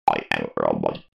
دانلود صدای ربات 33 از ساعد نیوز با لینک مستقیم و کیفیت بالا
جلوه های صوتی